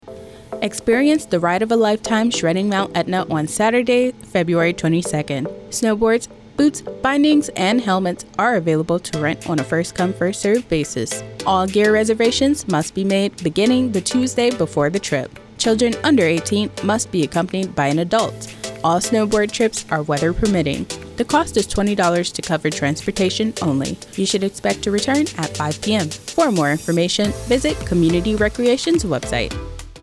NAVAL AIR STATION SIGONELLA, Italy (Jan. 23, 2025) Radio spot highlights Morale, Welfare and Recreation Sigonella's snowboarding tour.